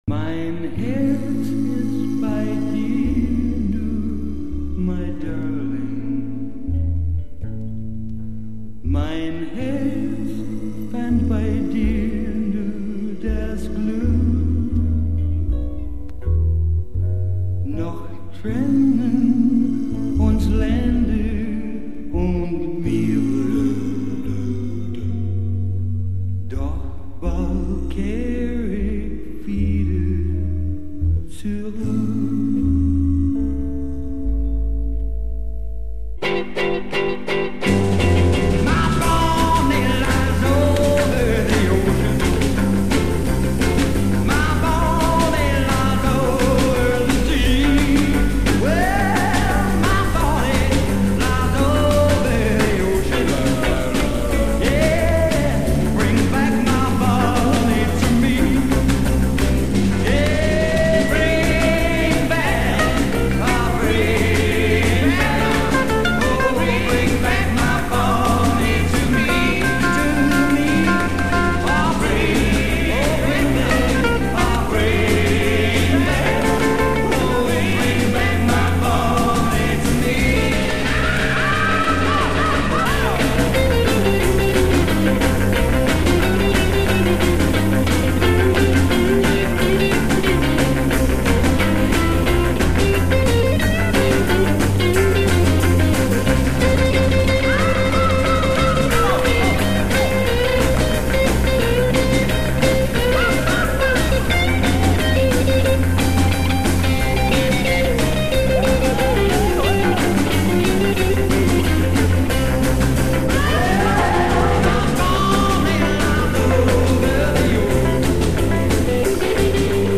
lead vocal and lead guitar
drums
A Verse 0:38 16 In tempo and C major b
12-bar blues guitar solo